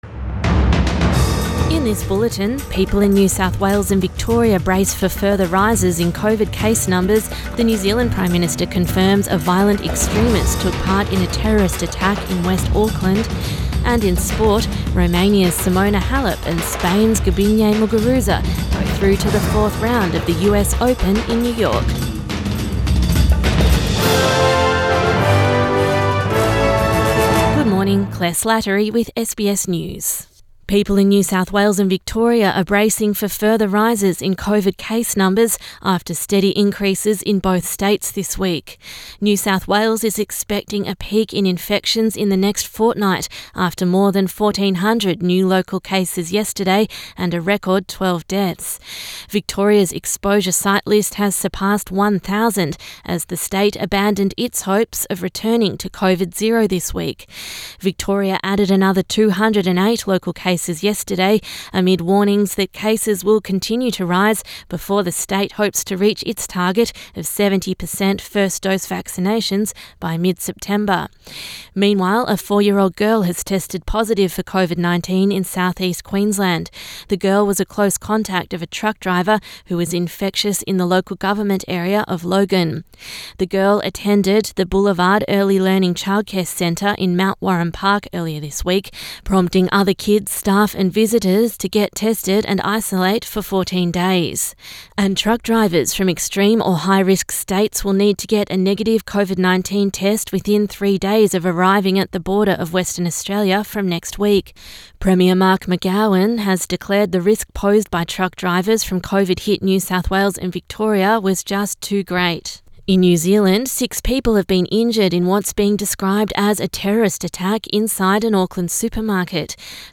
AM bulletin 4 September 2021